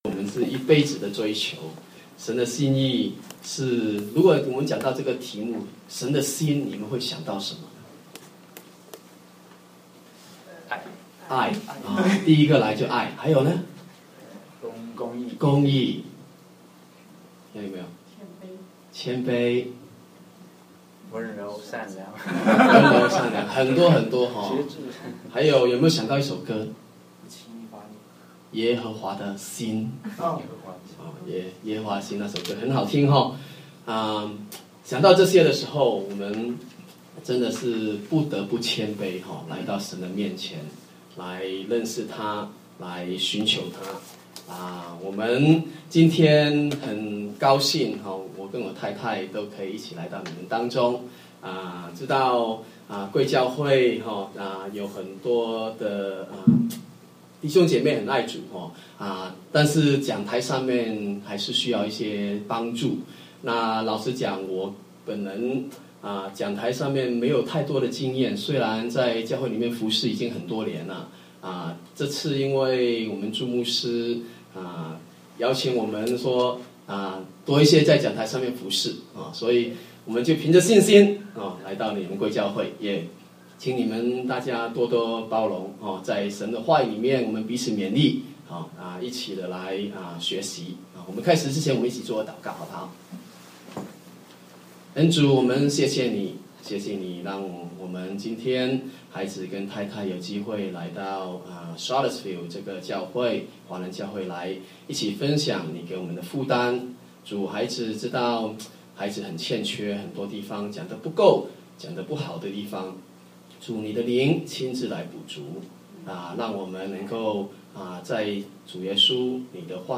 證道